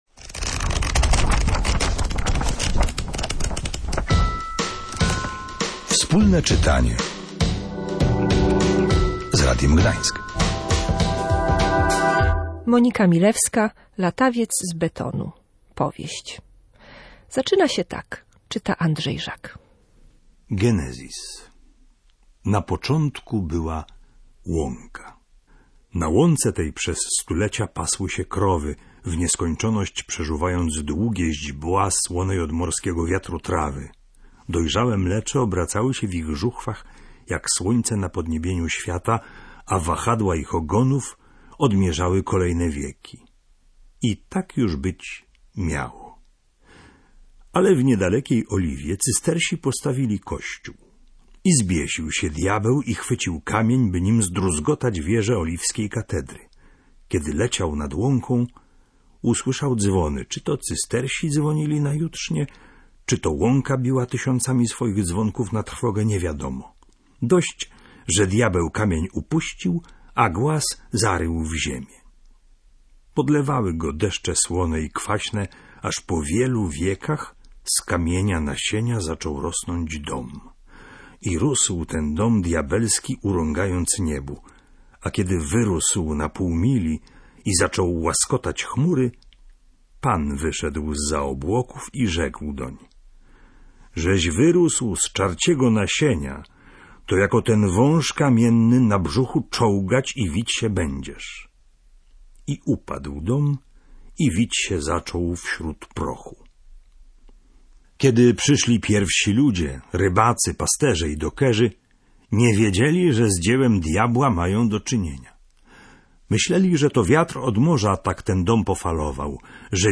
czytanie_3003.mp3